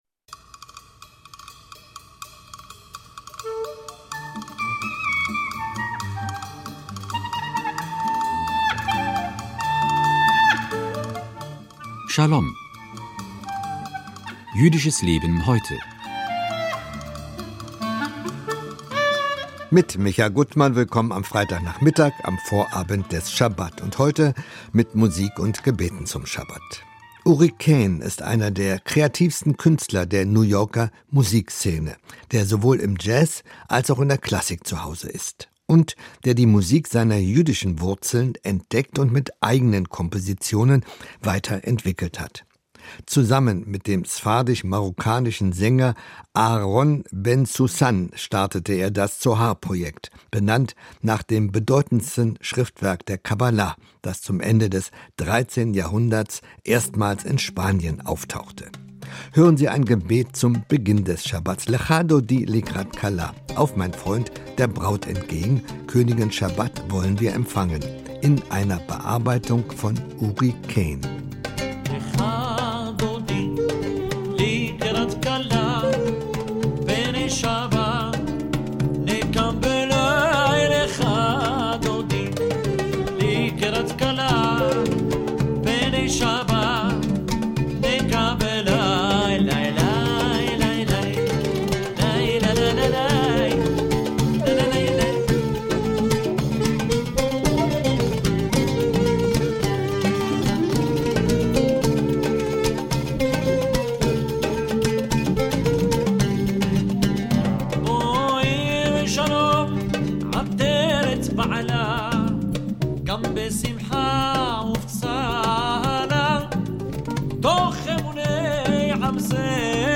Schalom 21.10.22 - Musik und Gebete zum Schabat